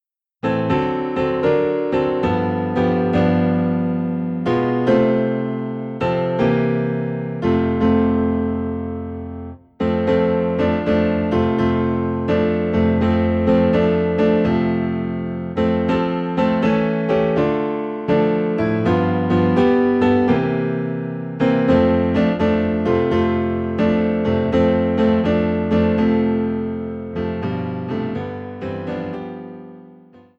Professionally recorded with introductions and all verses.
Faithful Piano Accompaniments for Worship